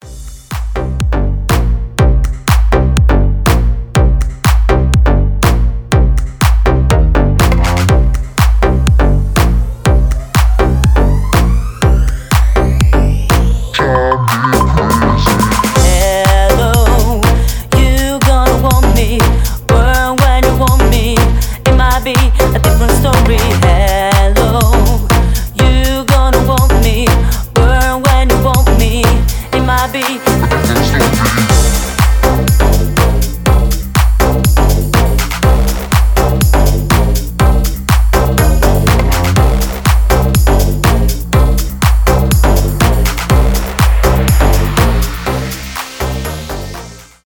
house , edm